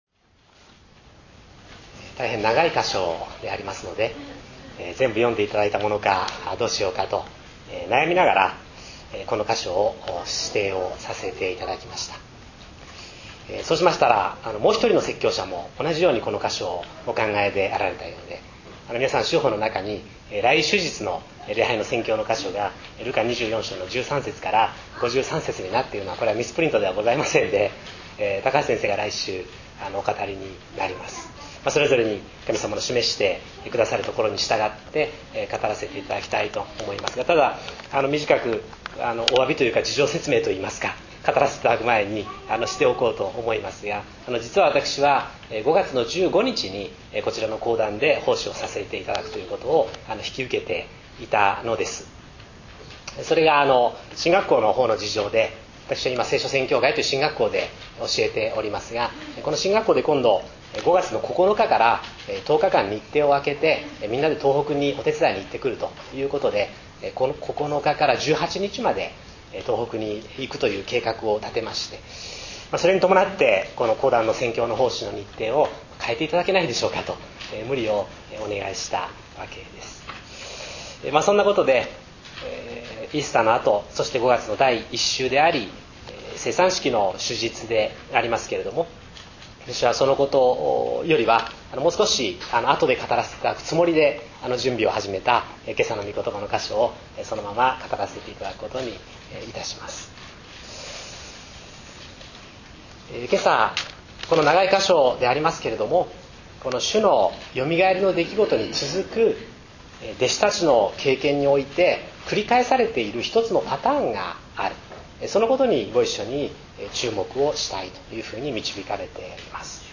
礼拝宣教録音－聞いて、信じて、共有して